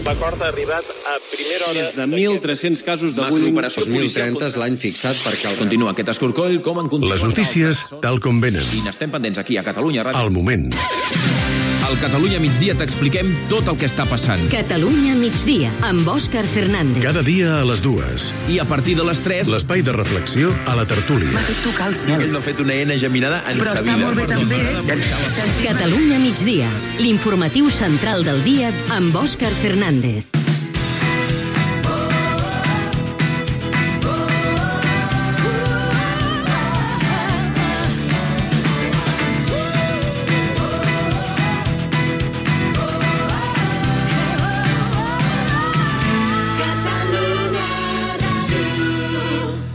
Promoció del programa i indicatiu de l'emissora.
FM